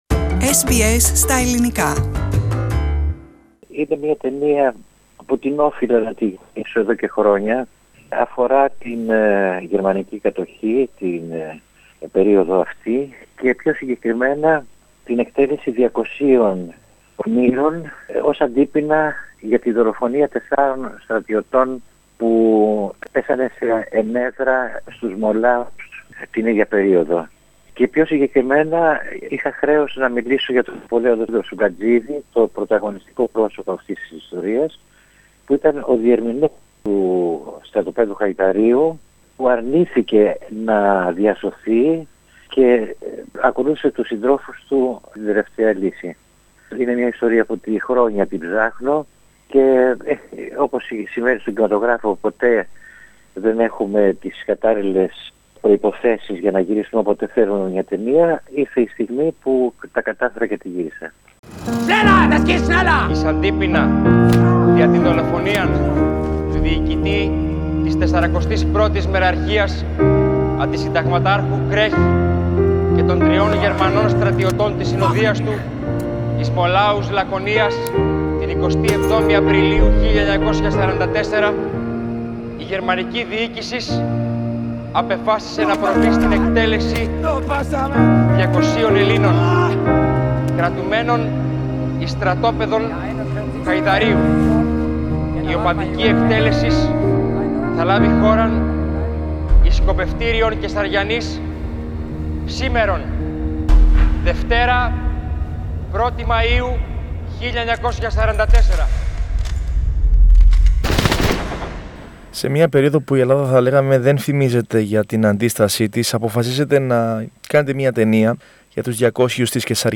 Σε ένα από τα πλέον διαβόητα και θλιβερά περιστατικά στα ελληνικά χρονικά του Β’ Παγκοσμίου Πολέμου, την εκτέλεση των 200 της Καισαριανής, αναφέρεται η νέα ταινία του Έλληνα σκηνοθέτη Παντελή Βούλγαρη, ο οποίος μιλά στο πρόγραμμά μας ενόψει της προβολής της στο πλαίσιο του 25ου Delphi Bank Φεστιβάλ Ελληνικού Κινηματογράφου.